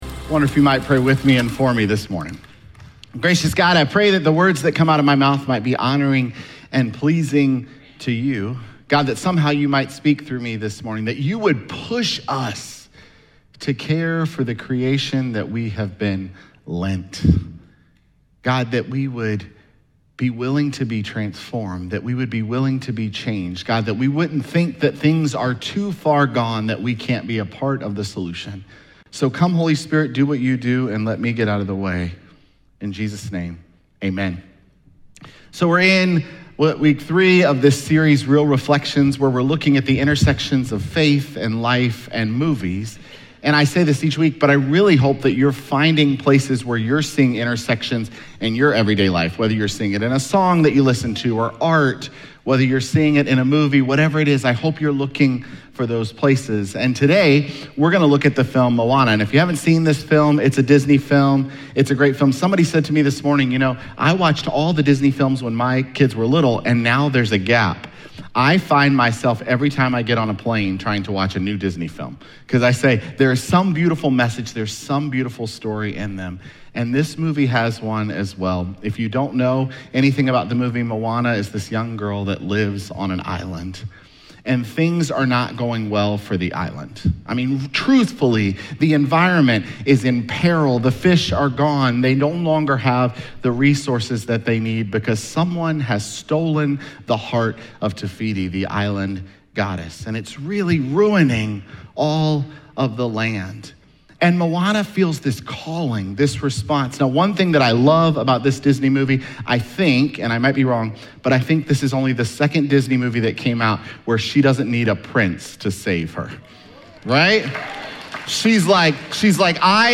Sermons
Jun29SermonPodcast.mp3